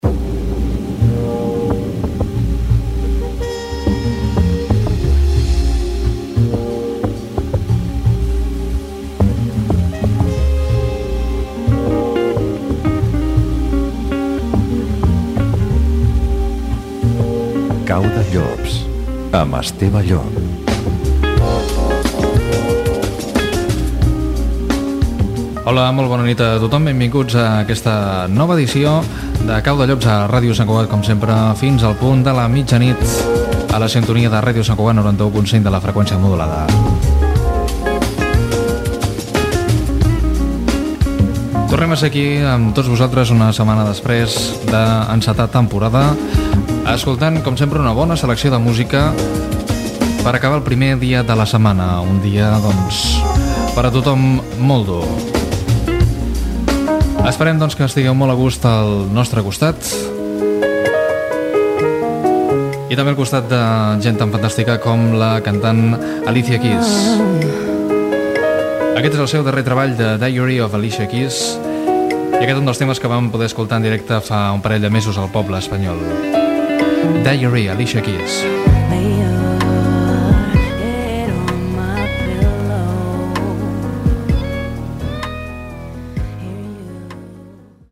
Careta del programa, presentació i tema musical
Musical